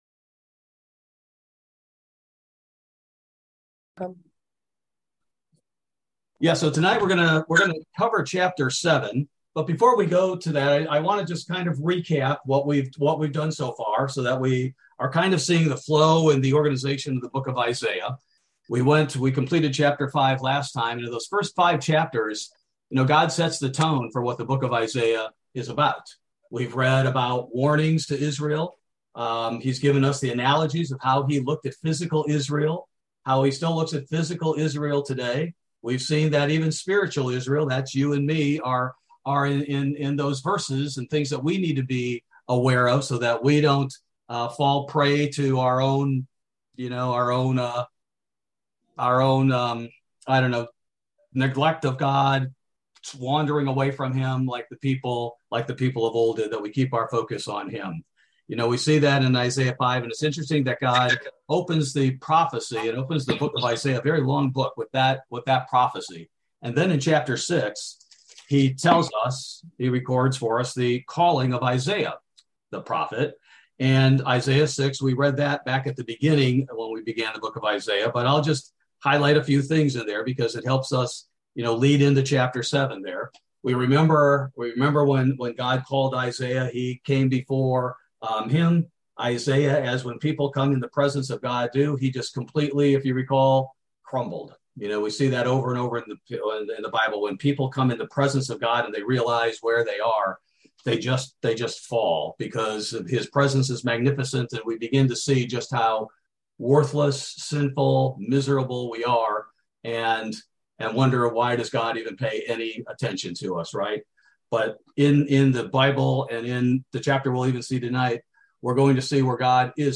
Bible Study: August 3, 2022